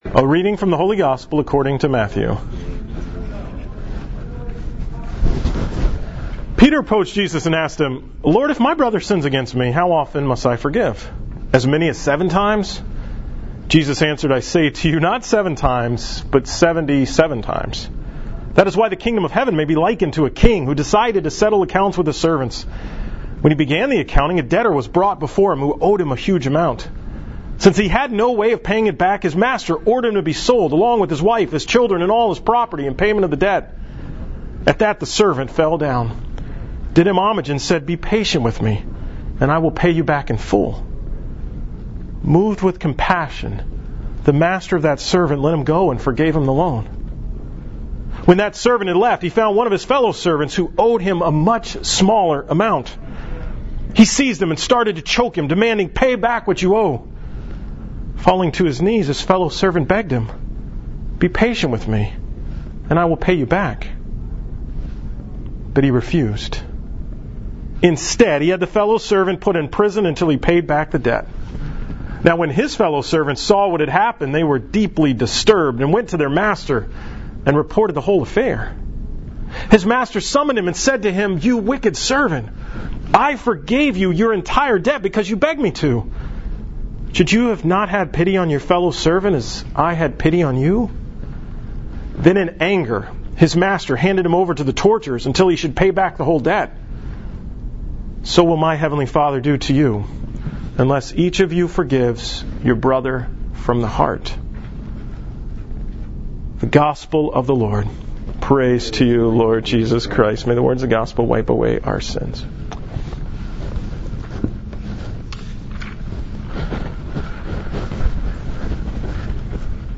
From Mass on September 17th, 2017